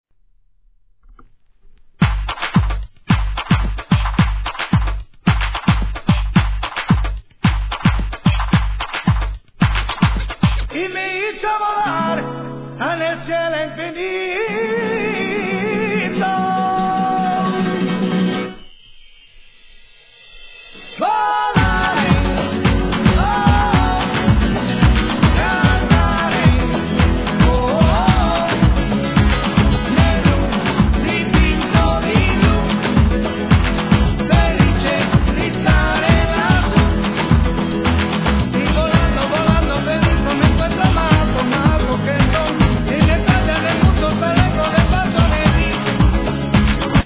1. HIP HOP/R&B
フロア映え抜群のクラップビートがハマった